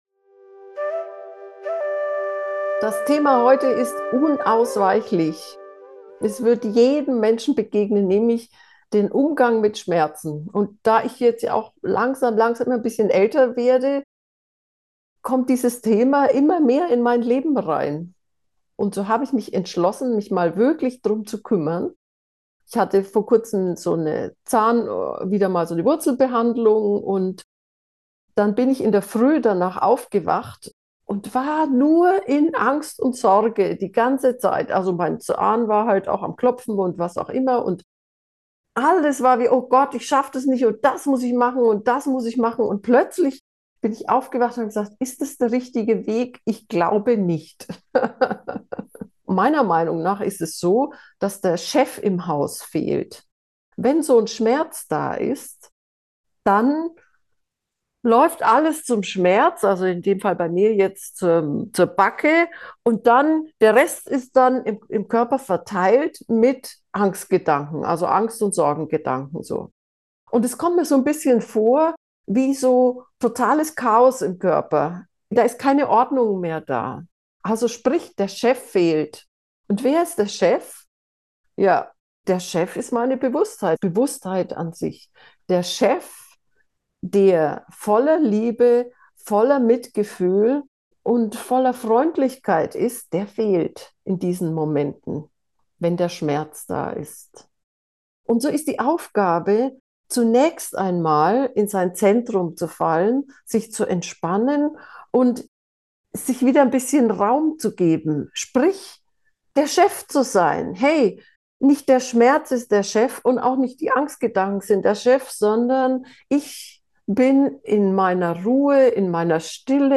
schmerzen-gefuehrte-meditation